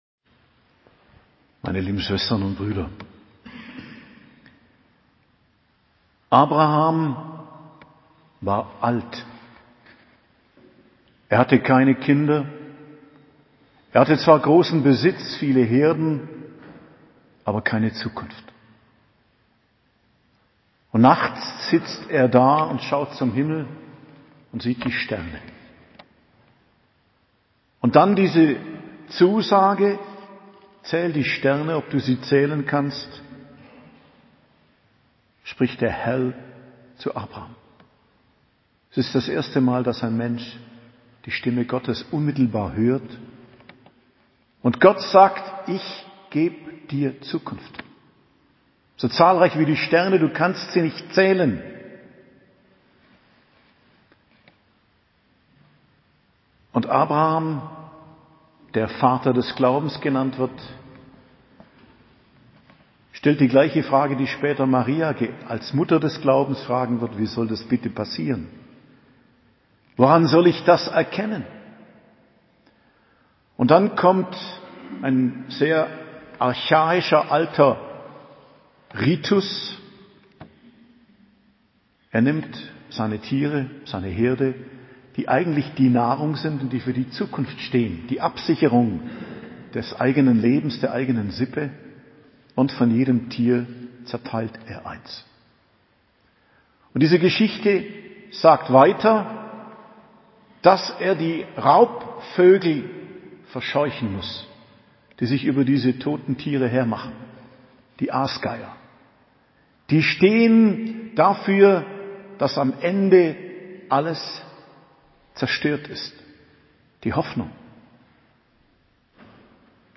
Predigt zum 2. Fastensonntag, 13.03.2022 ~ Geistliches Zentrum Kloster Heiligkreuztal Podcast